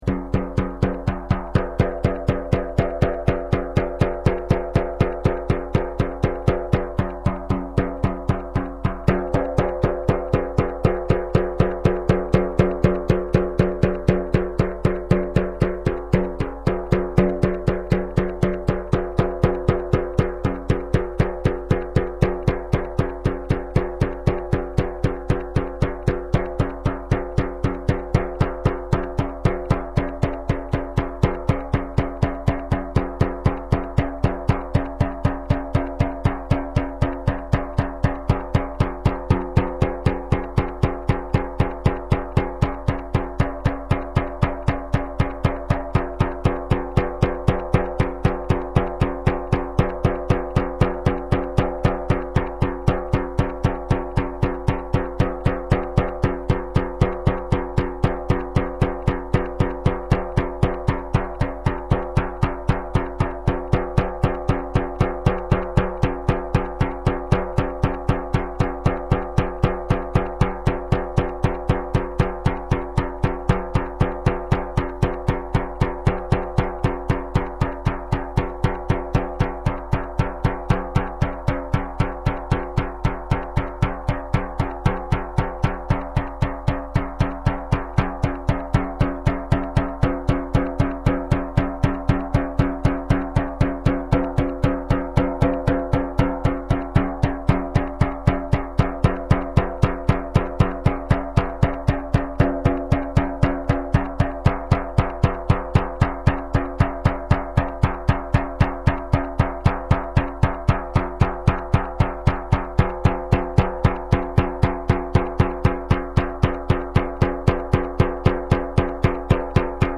tambour-chamanique.mp3